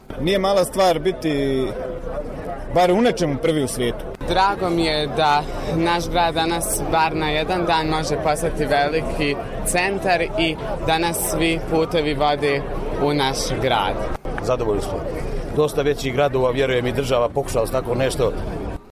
Prnjavorčani, kao i brojni gosti koji su došli na ovaj događaj, nisu krili zadovoljstvo što je rekord Ginisa u kuhanju riblje čorbe osvojen:
Reakcija na Ginisa